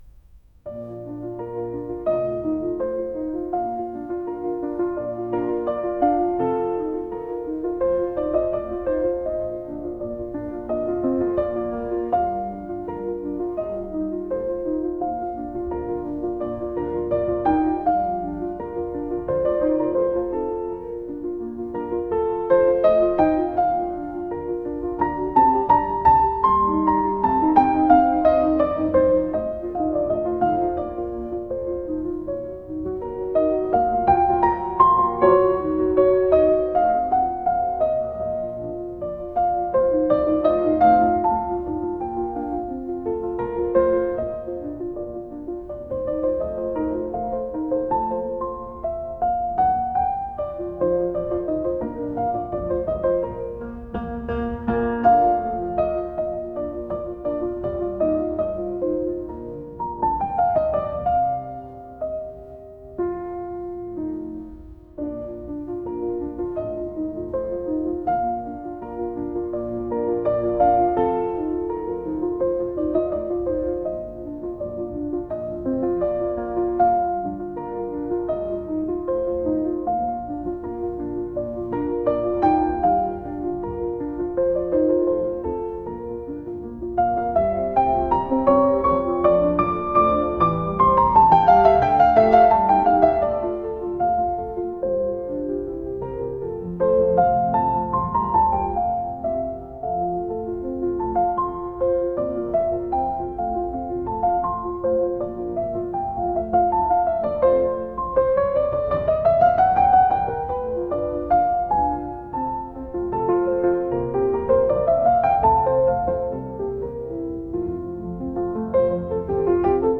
classical | cinematic | ambient